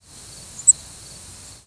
Cape May Warbler diurnal flight calls
Bird in flight.